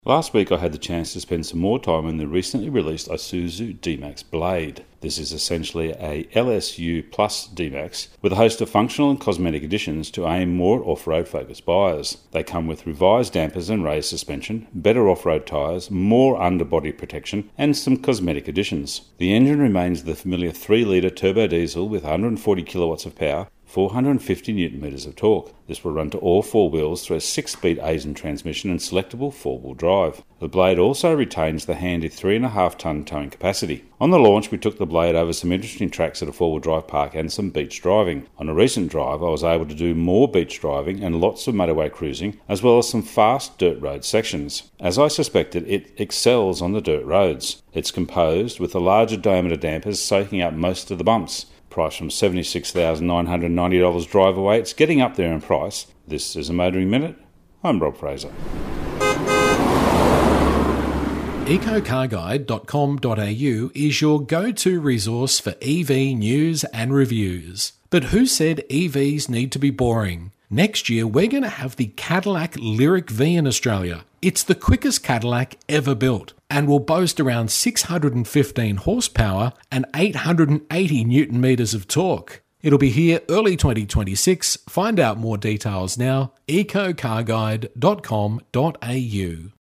Motoring Minute is heard around Australia every day on over 100 radio channels.